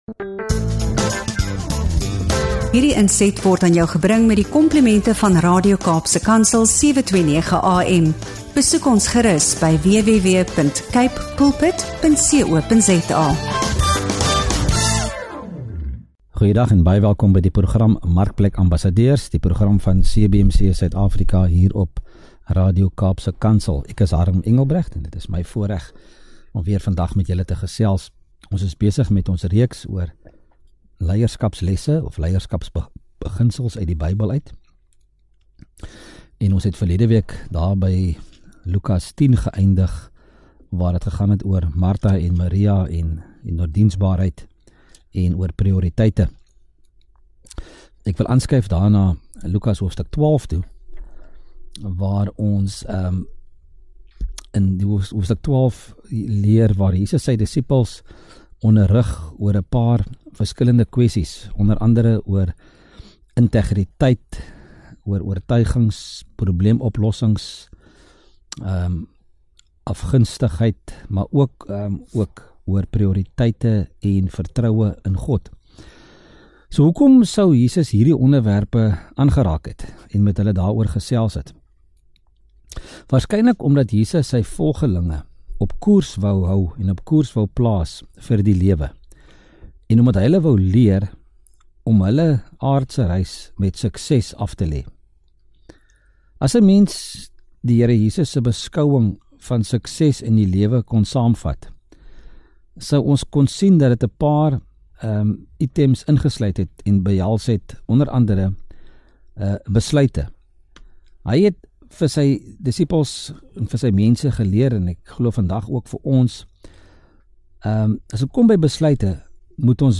Sluit aan by hierdie inspirerende gesprek wat jou sal help om jou roeping in die markplek en lewe met meer duidelikheid en doel te leef.